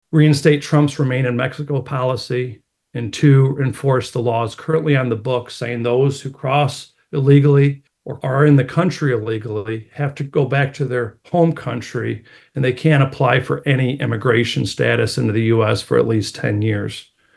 AUDIO: Senator Nesbitt, Van Buren County sheriff take part in illegal immigration press conference